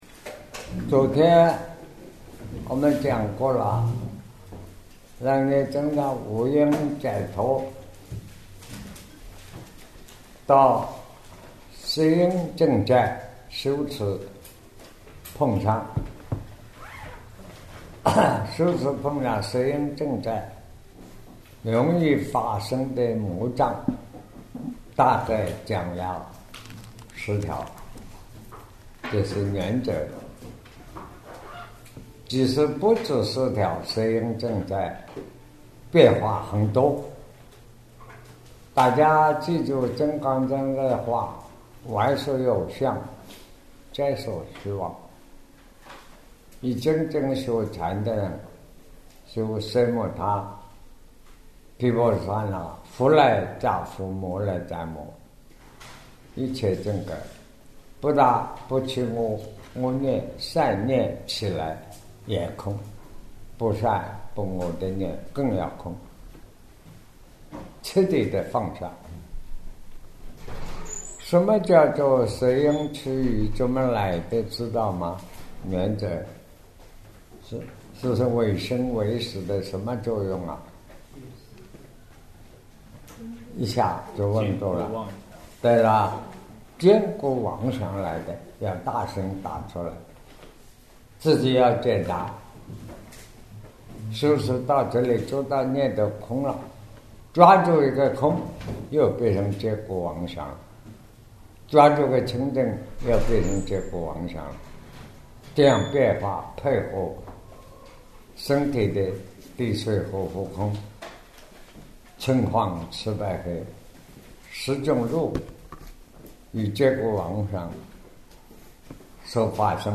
南怀瑾先生2009年讲楞严经141 卷九 五阴解脱 受阴区宇魔境